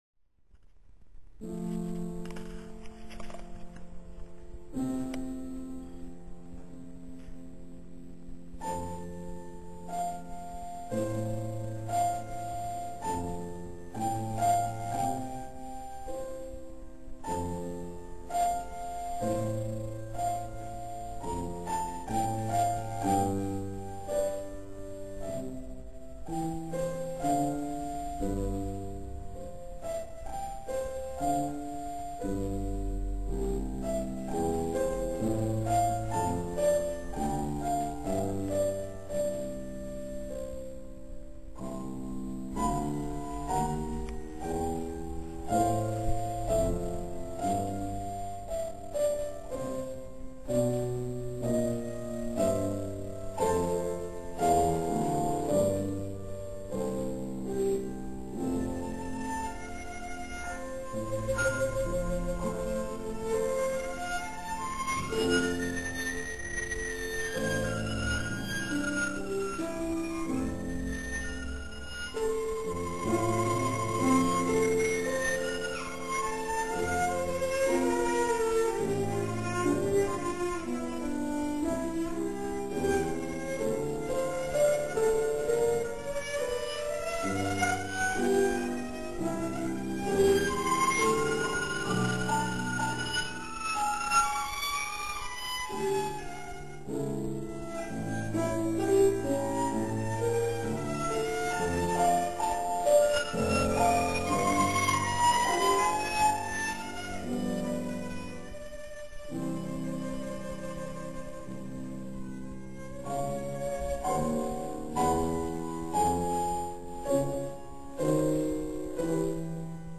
19. komorní koncert na radnici v Modřicích
- ukázkové amatérské nahrávky, v ročence CD Modřice 2006 doplněno: